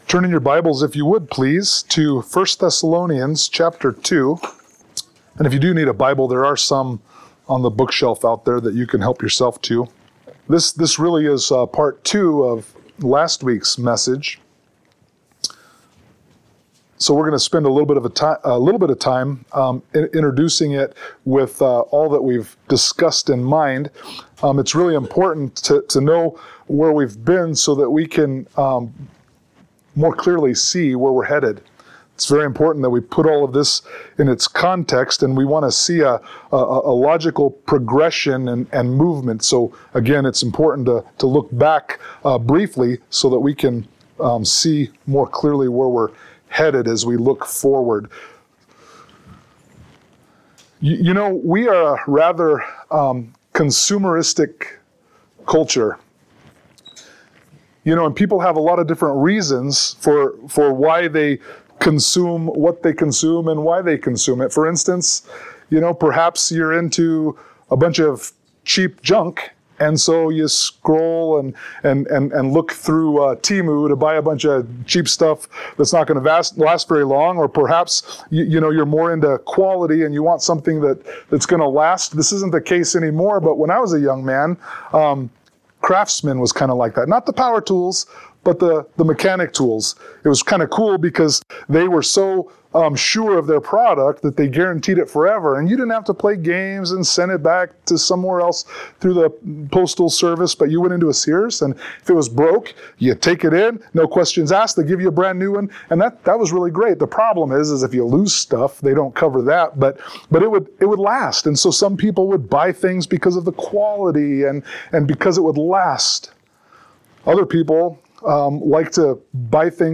Sermon-2_23_25.mp3